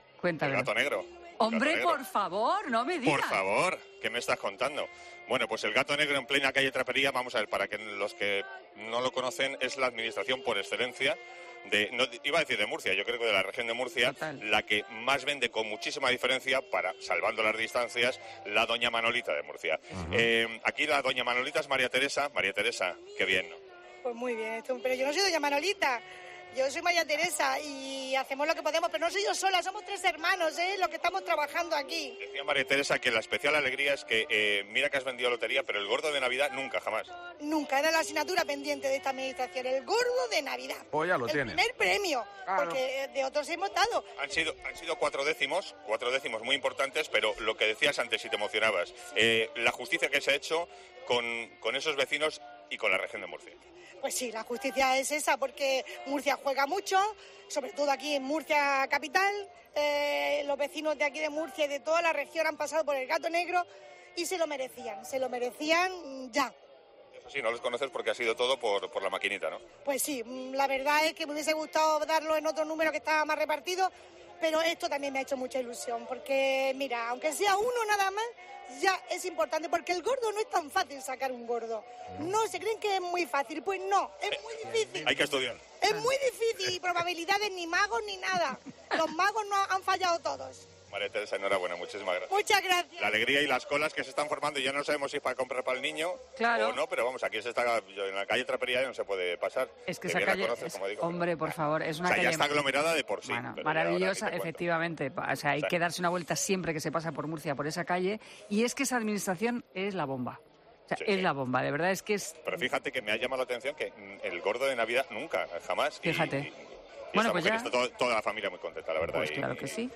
en el programa especial COPE